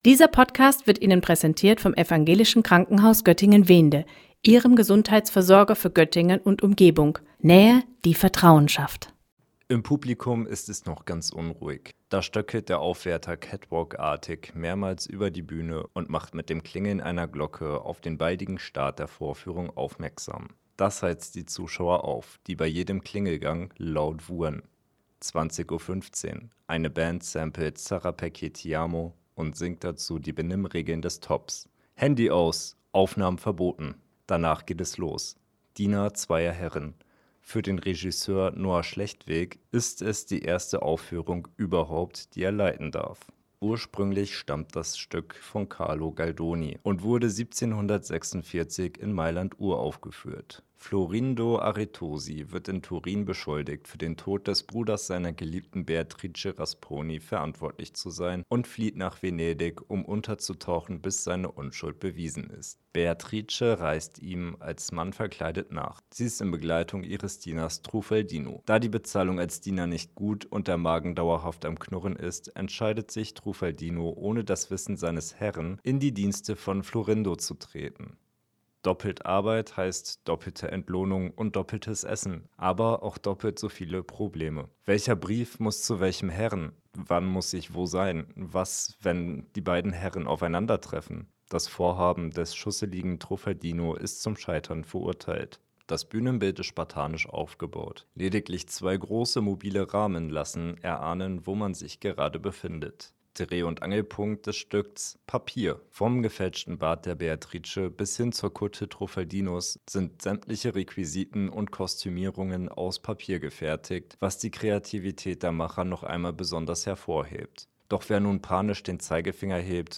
Beiträge > Rezension: Diener zweier Herren im ThOP - StadtRadio Göttingen